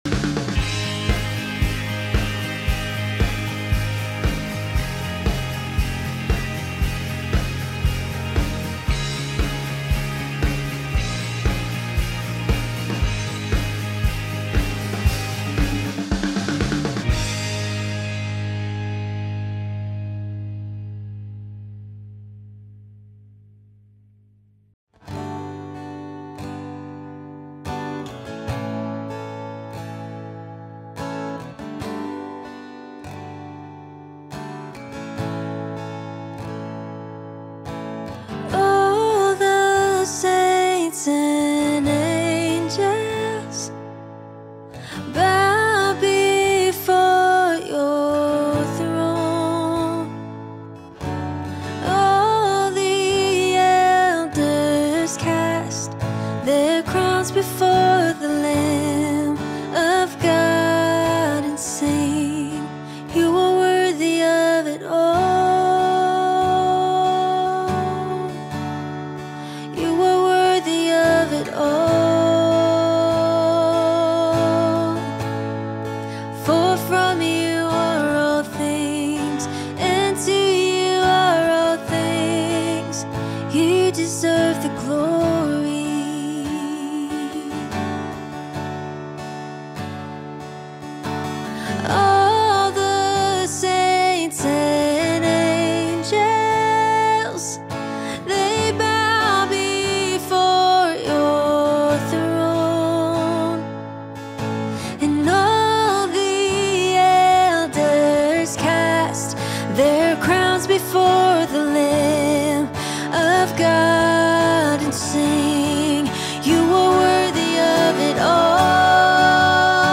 Sunday Service I Raising Esther's and David's For Such A Time As This